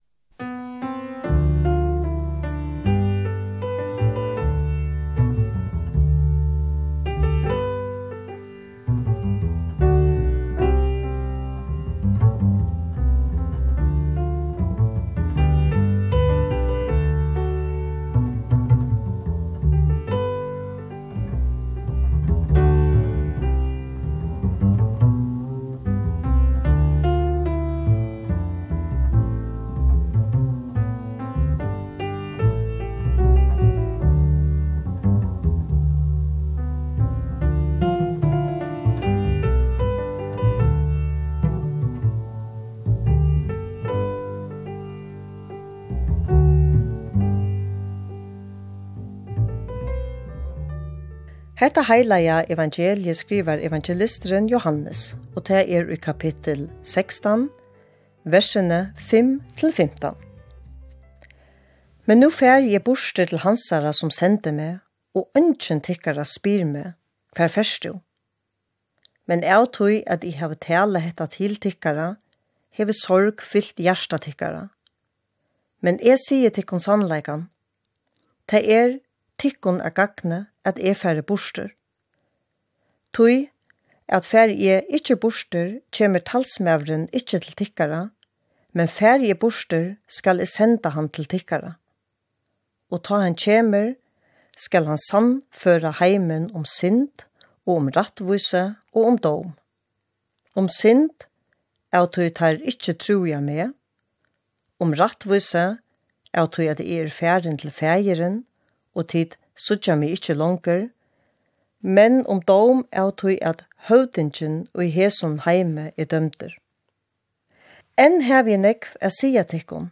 Presturin skrivar og lesur tekstin. Sendingin byrjar við, at lisið verður prædikuteksturin til teir ávísu sunnudagarnar. Teksturin verður tulkaður og viðgjørdur. Sendingin endar við sangi ella sálmi, sum prestur velur.